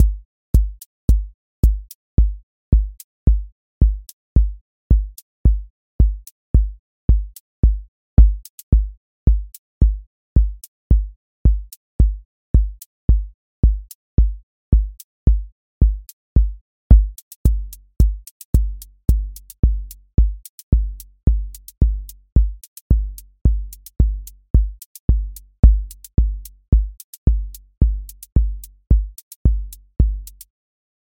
Four Floor Drive QA Listening Test house Template: four_on_floor April 18, 2026 ← Back to all listening tests Audio Four Floor Drive Open MP3 directly Selected Components macro_house_four_on_floor voice_kick_808 voice_hat_rimshot voice_sub_pulse Test Notes What This Test Is Four Floor Drive Selected Components macro_house_four_on_floor voice_kick_808 voice_hat_rimshot voice_sub_pulse